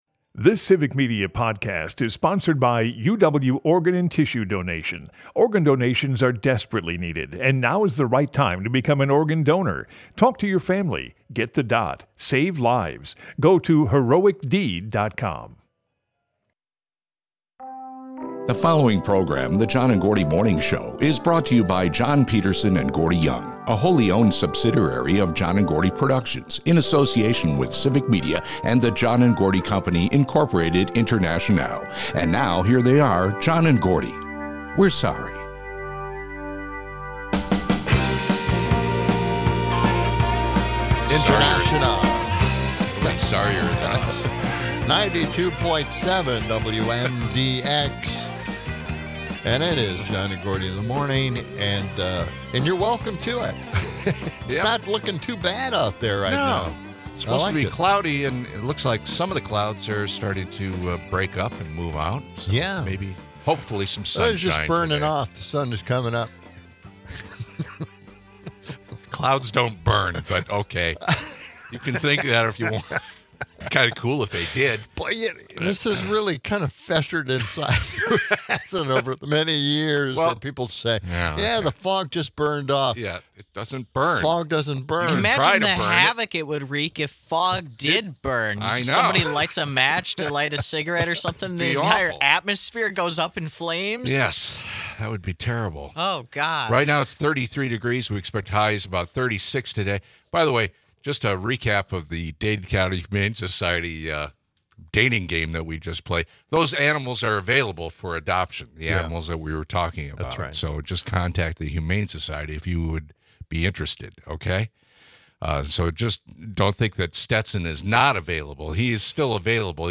After taking some calls, we close out the show discussing oil and gas prices, green energy, and how Trump's policies are counterintuitive on both of them.